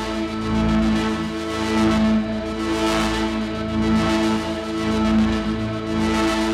Index of /musicradar/dystopian-drone-samples/Tempo Loops/110bpm
DD_TempoDroneB_110-A.wav